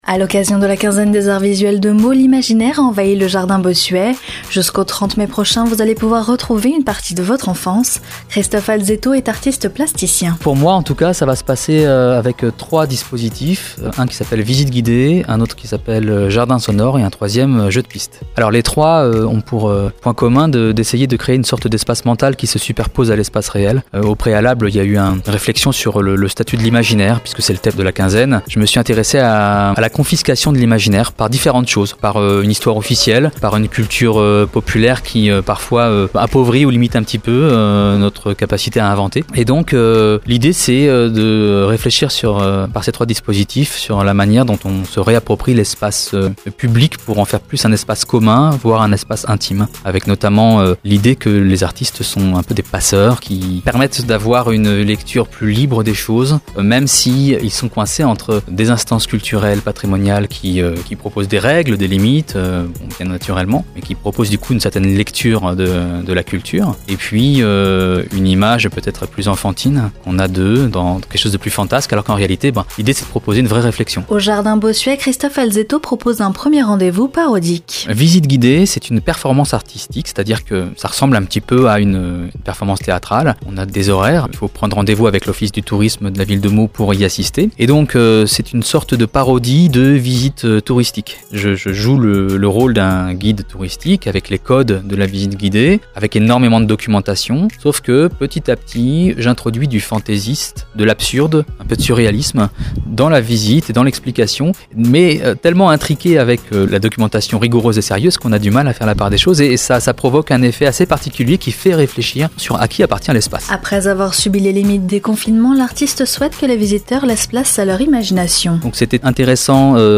25/05/21 Interview CrazyRadio